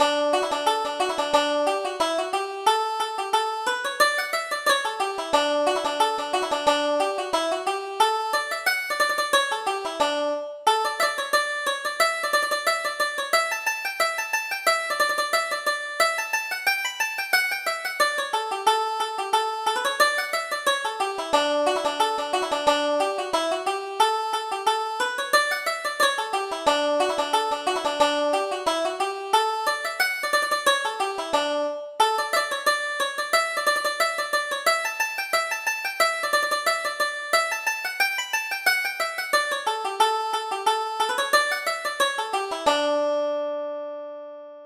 Reel: Toss the Feathers - 2nd Setting